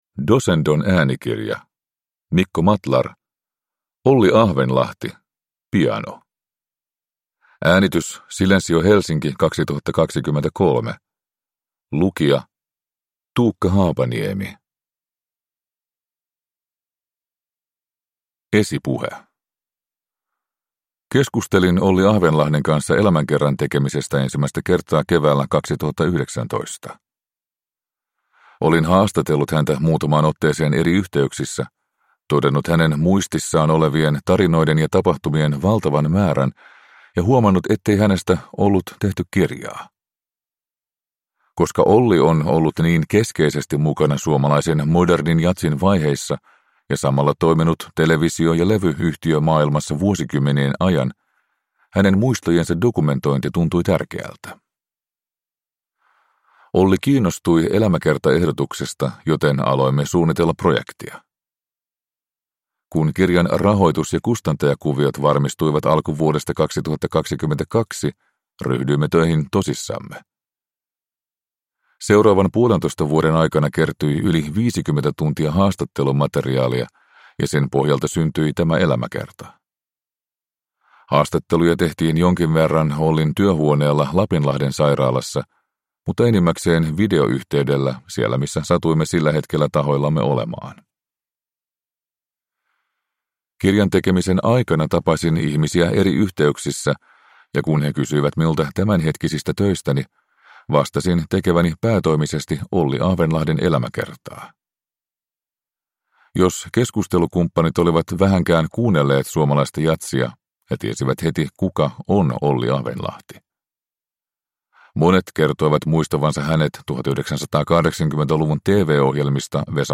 Olli Ahvenlahti, piano – Ljudbok – Laddas ner